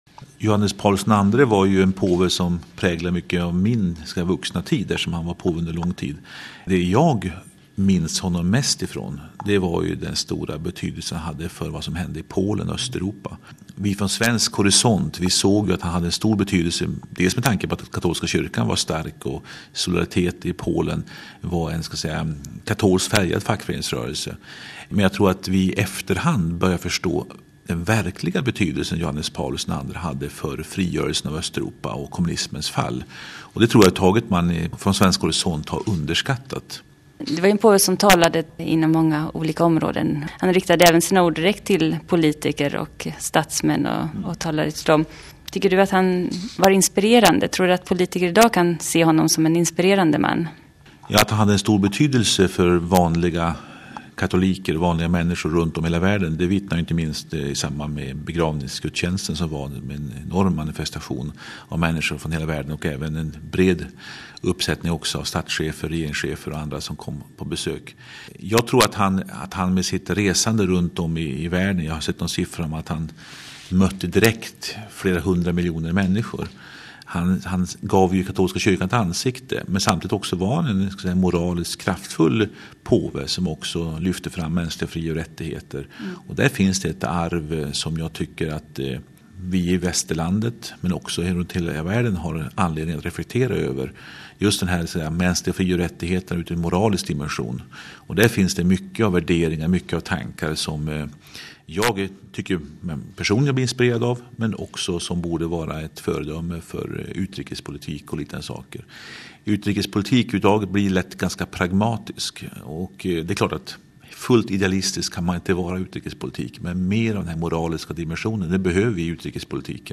Intervju med Civilminister Stefan Attefall - i Rom för saligförklaringen